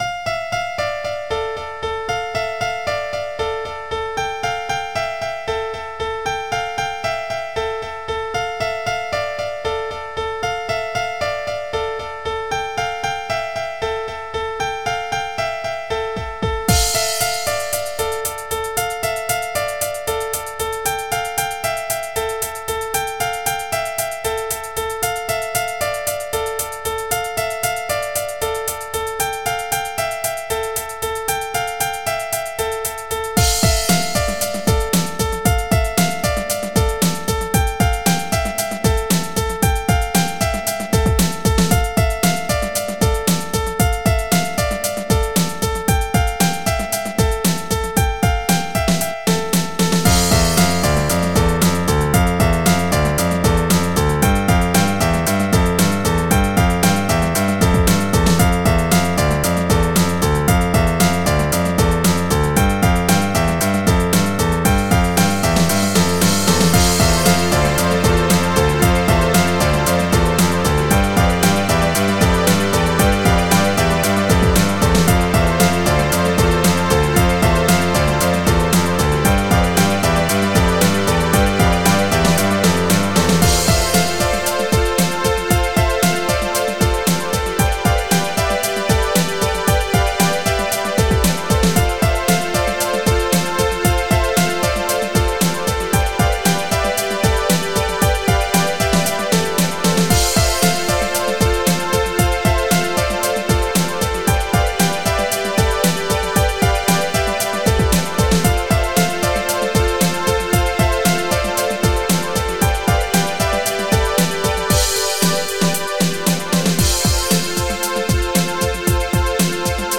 s3m (Scream Tracker 3)
Buben 13
Cinel dlouhy 7
Piano 6 hluboke
For piano melody thanx to